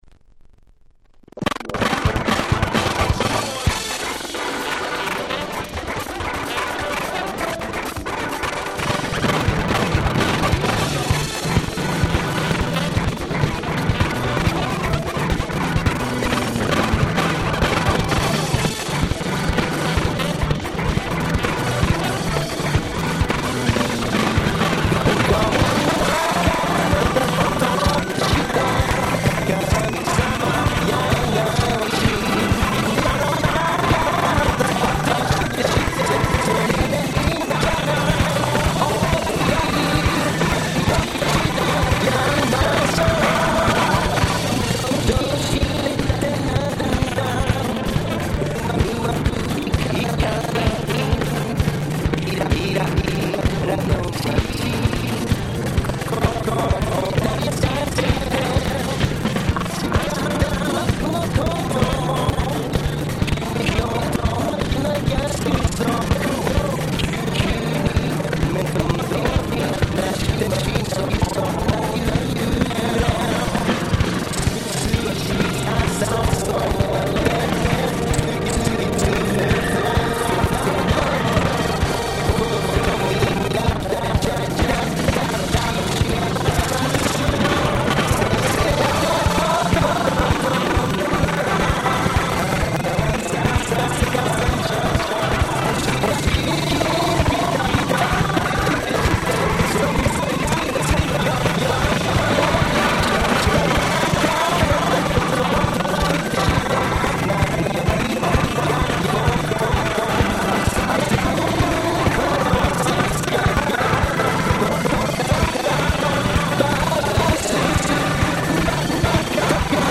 Dance Classicsの往年の名曲達を面白楽しく日本語で替え歌してしまった非常にユーモア溢れるシリーズ！(笑)